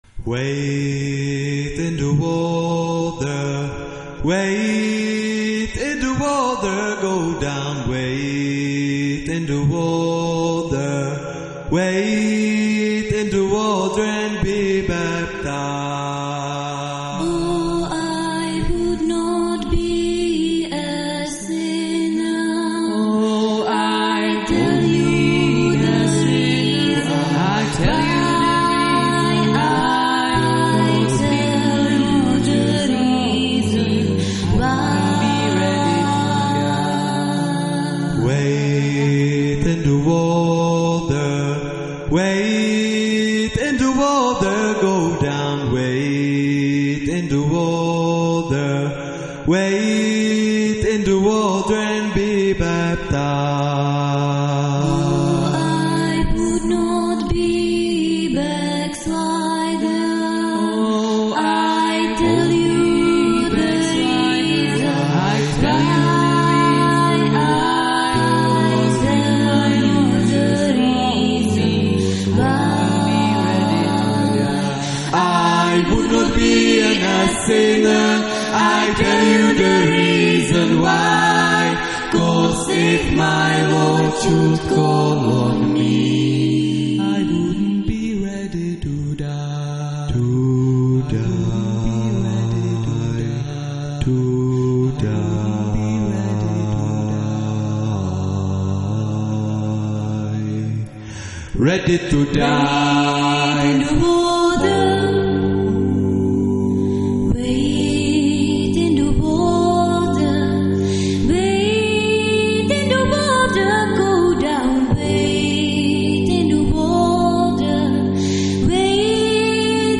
TÁBOR/SEZIMOVO ÚSTÍ - V loňském roce na táborské Bambiriádě 2006 opět vystoupili vítězové Dětské porty z Českého Krumlova - Kaplická kapela LIFE